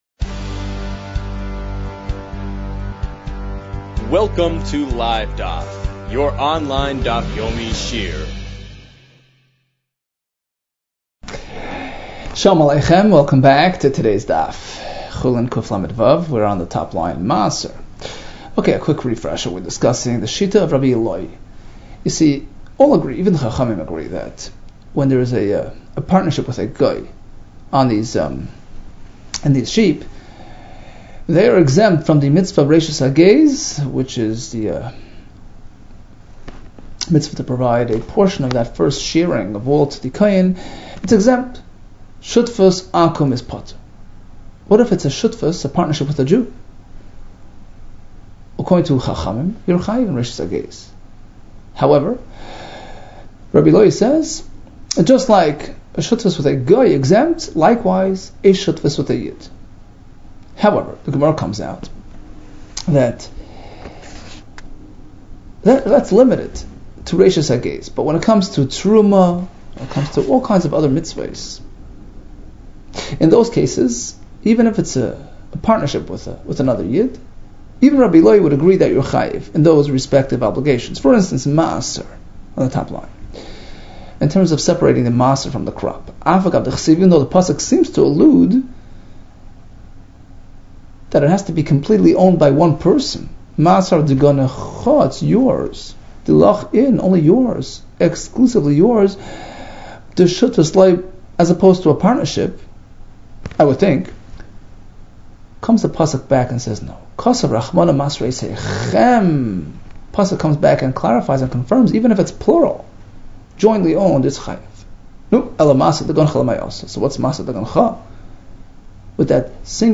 Chulin 136 - חולין קלו | Daf Yomi Online Shiur | Livedaf